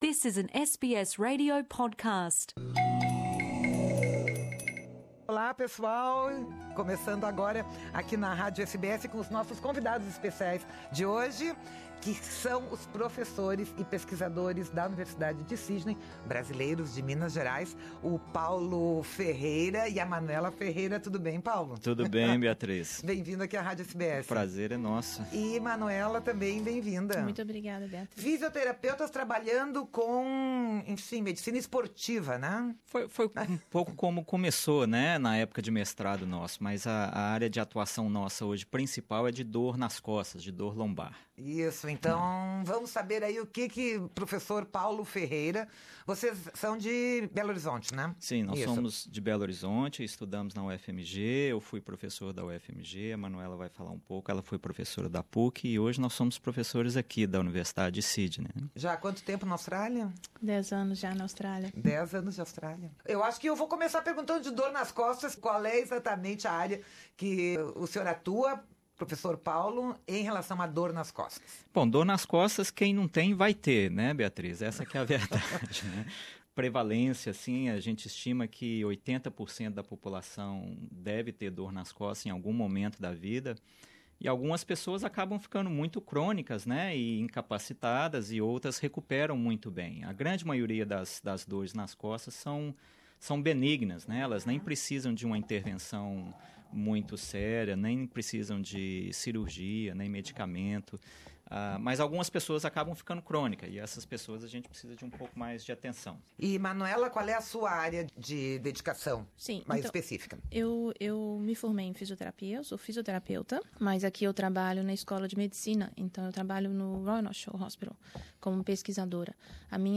Nessa entrevista eles revelam as causas e tratamentos do mal que aflige 80% da população mundial.